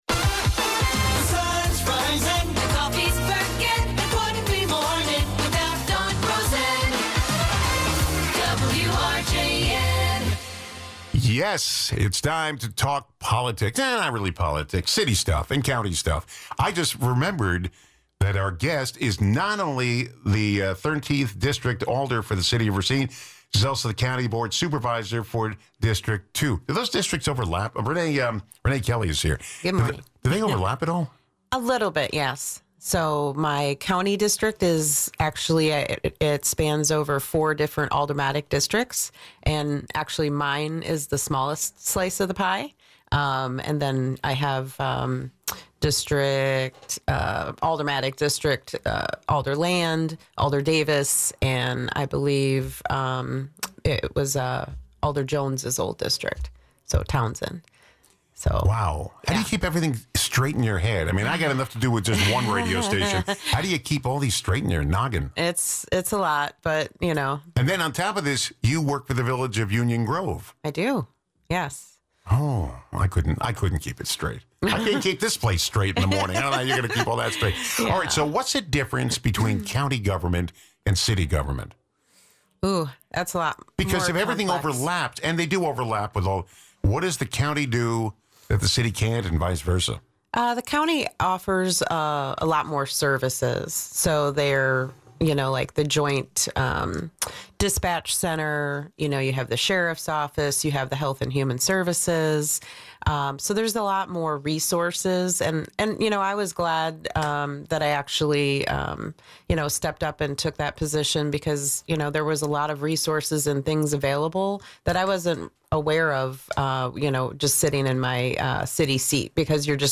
Guests: Renee Kelly